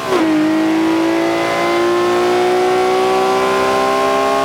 Index of /server/sound/vehicles/lwcars/lam_reventon